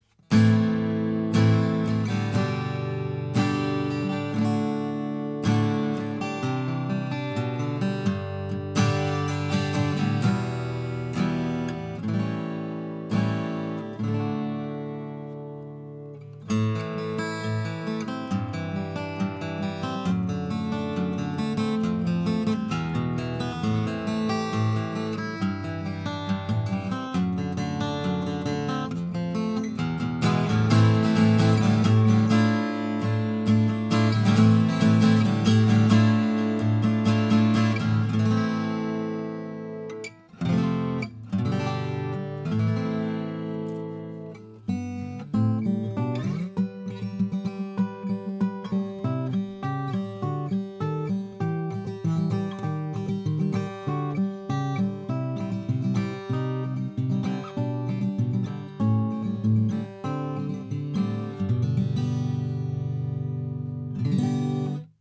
gibson SJ-200 m Quilt Limited Edition with a naturally aged Sitka Spruce top and figured Maple captures the feel and tone of the post-war classic.
Gibson_J-200M_-_Sound-Impression.mp3